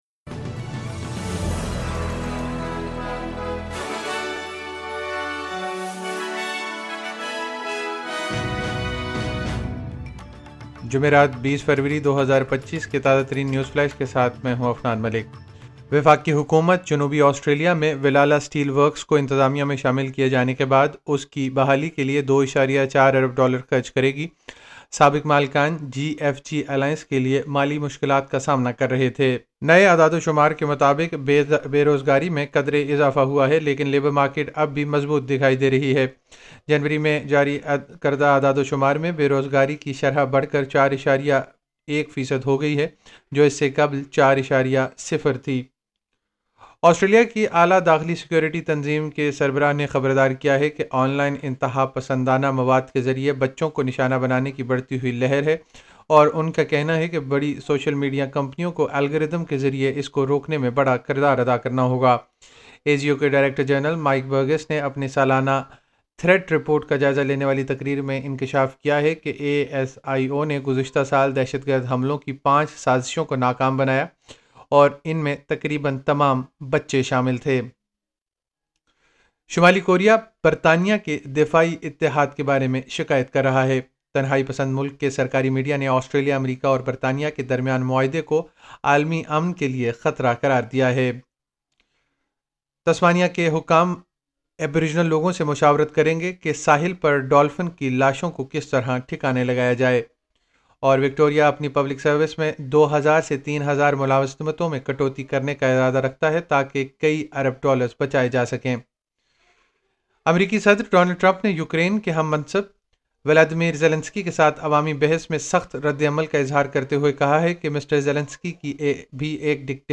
مختصر خبریں: 20 فروری 2025